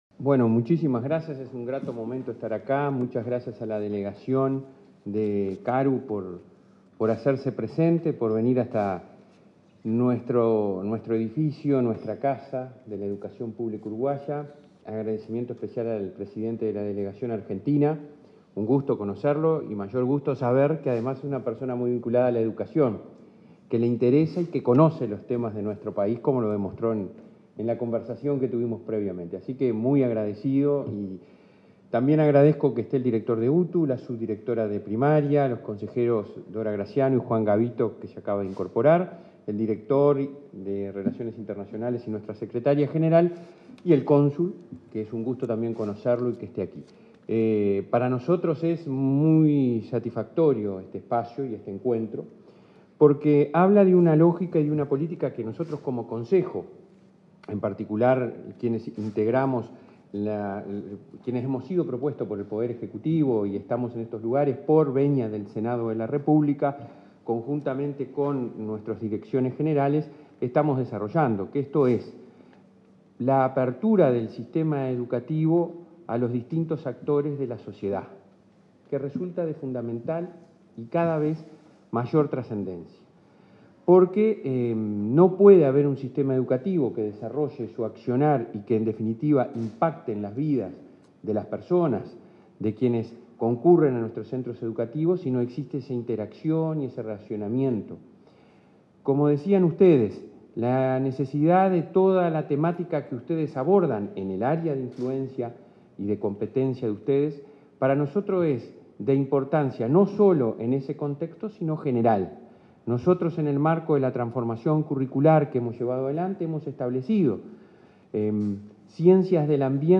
Palabras del presidente de la ANEP, Robert Silva
Palabras del presidente de la ANEP, Robert Silva 28/02/2023 Compartir Facebook X Copiar enlace WhatsApp LinkedIn En el marco de la firma de un convenio de cooperación con la Comisión Administradora del Río Uruguay (CARU), con el objetivo de promover el desarrollo de la educación ambiental, este 28 de febrero, se expresó el presidente de la Administración Nacional de la Educación Pública (ANEP), Robert Silva.